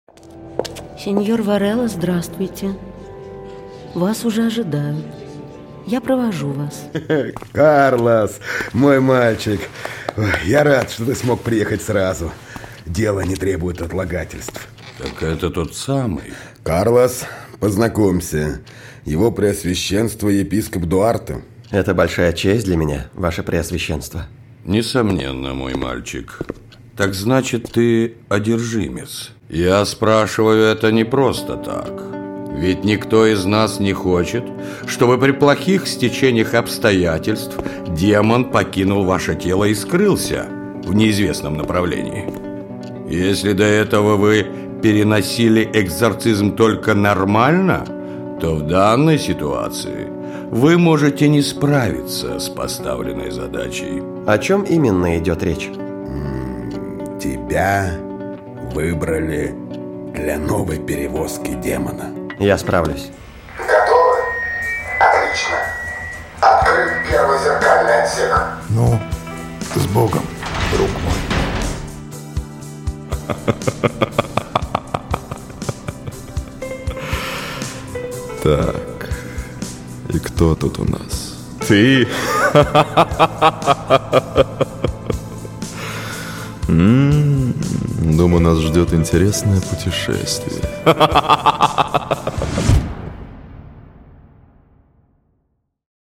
Aудиокнига Часовщик. 2-я серия. Рассказ «Демон внутри» Автор Артем Каргин.